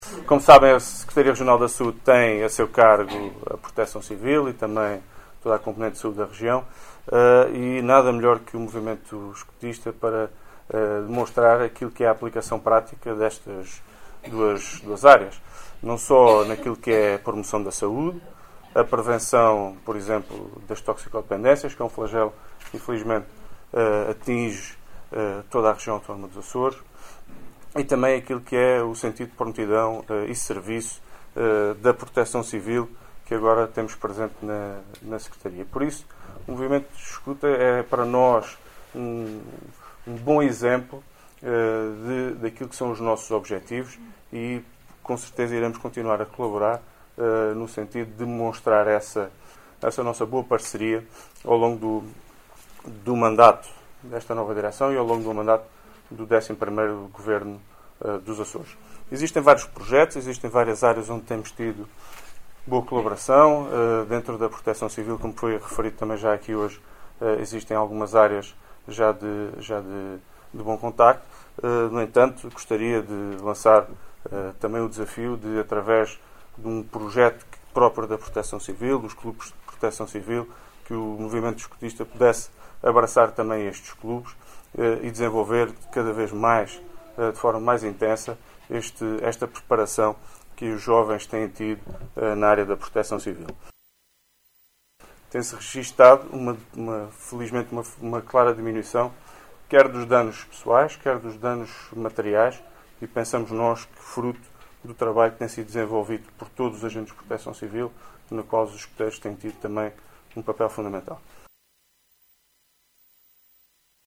Luís Cabral, que falava na cerimónia de posse dos dirigentes do Corpo Nacional de Escutas (CNE) nos Açores, afirmou que os escuteiros já dão uma boa colaboração no âmbito da Proteção Civil, mas considerou que seria importante “que o movimento escutista se associasse também ao projeto que está a ser dinamizado dos Clubes de Proteção Civil”.